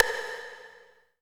34 V.STICK-R.wav